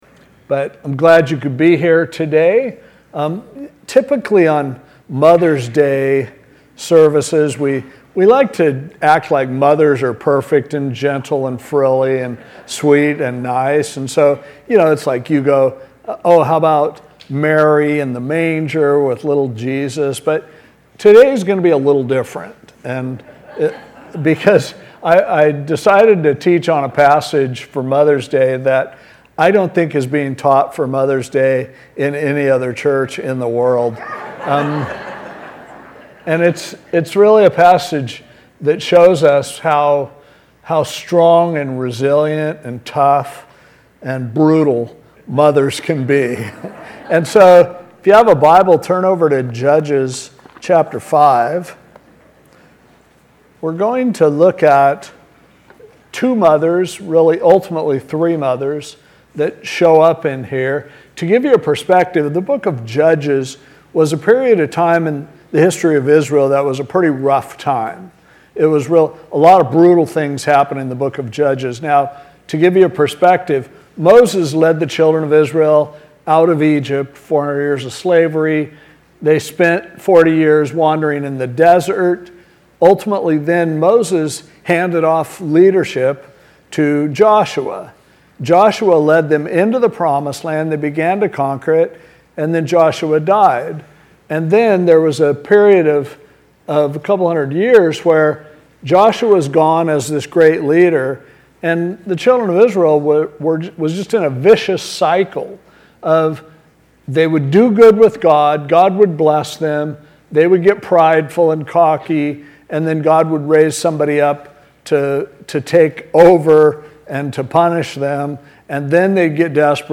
Sixteen Best Chapel Pointe Podcasts For 2025.